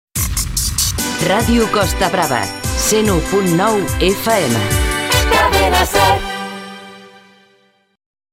Identificació i freqüència